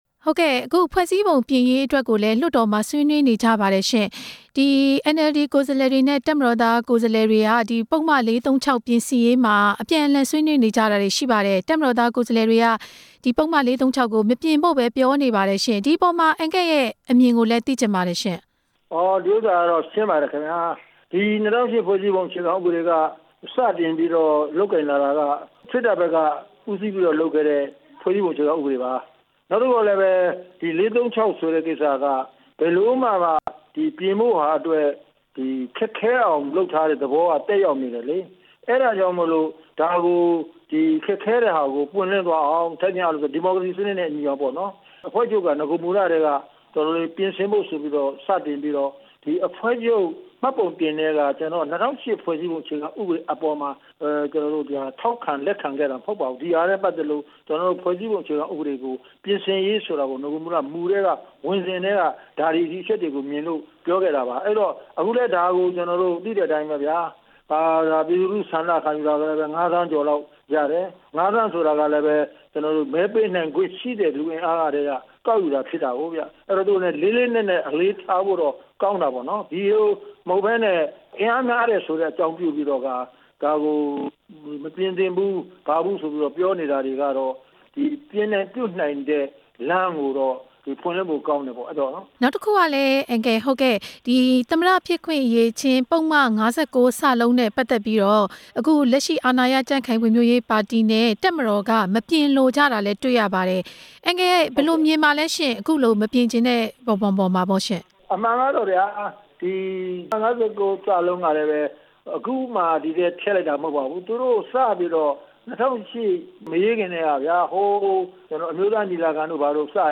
ဖွဲ့စည်းပုံအခြေခံဥပဒေ ပြုပြင်ပြောင်းလဲရေးကိစ္စနဲ့ ပတ်သက်ပြီး မေးမြန်းချက်